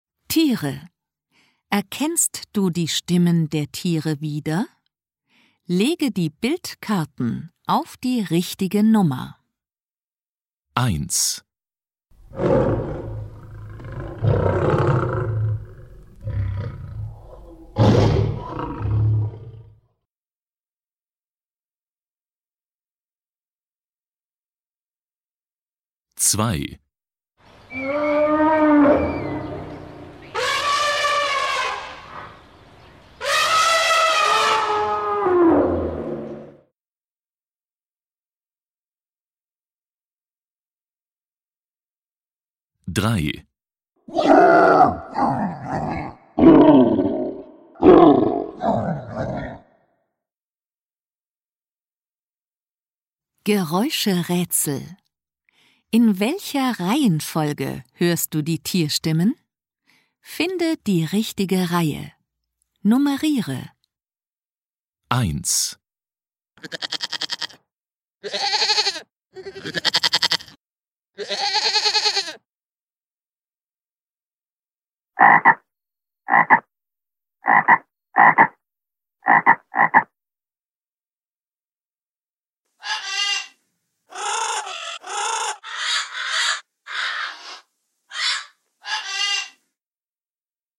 3 CDs mit Höraufgaben (auch als Download verfügbar)